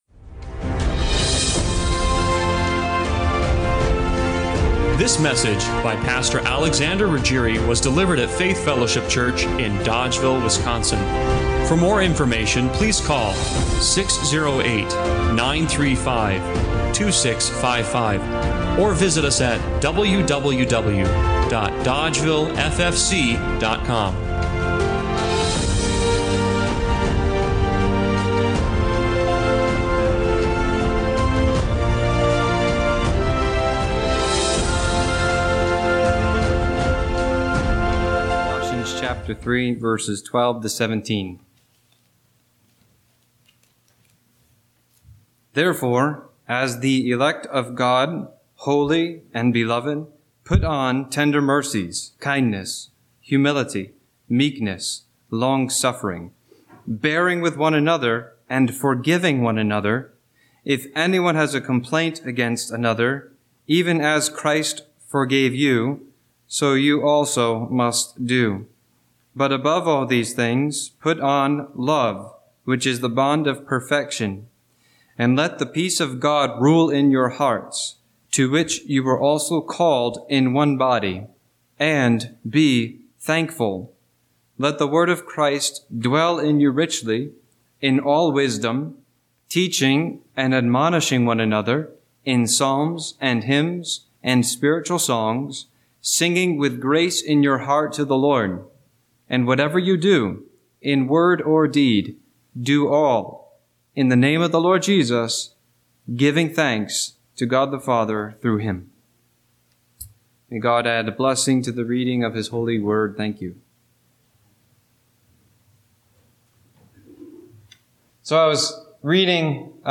Colossians 3:17 Service Type: Sunday Morning Worship Did you know that giving thanks is a command?